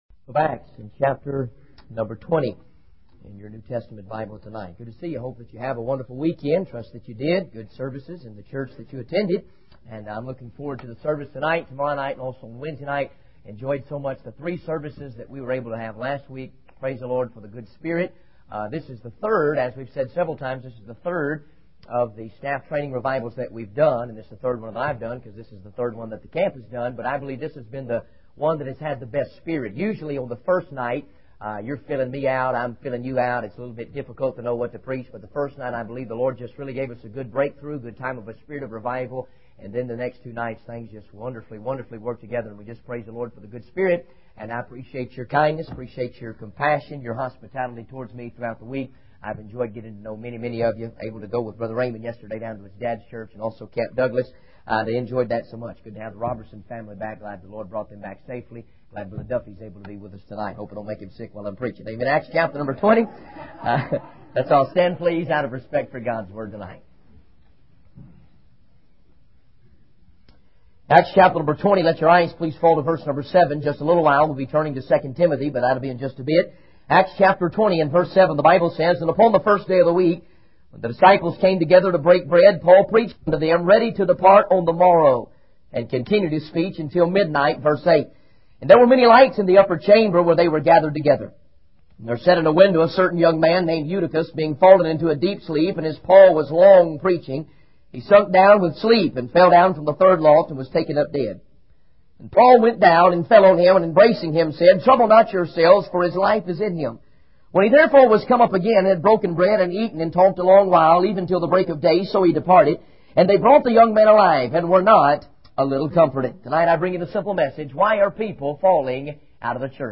In this sermon, the speaker begins by talking about his love for watching boxing matches and how he enjoys seeing a good fight that goes several rounds. He then transitions to the topic of the Christian life, emphasizing that it is not always easy to read the Bible, pray, give, or show love and compassion towards others. He warns against being complacent and not staying in the fight for righteousness.